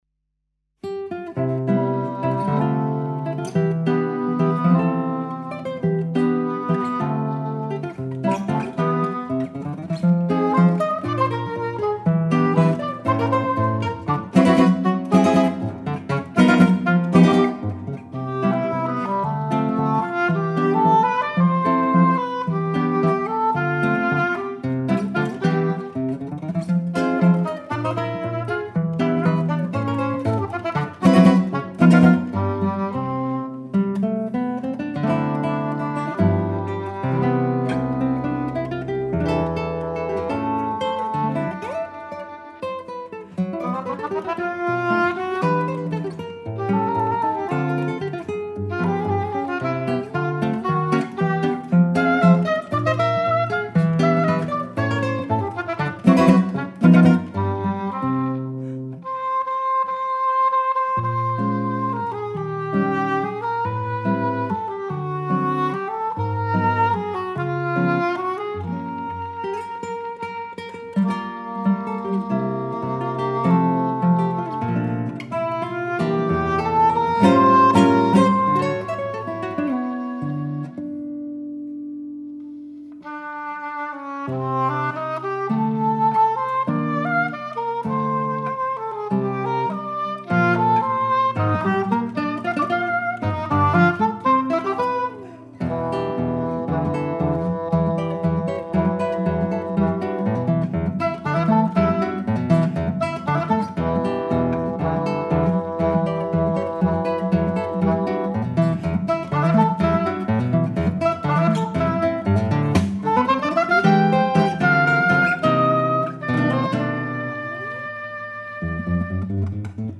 Music from South America
oboe
guitars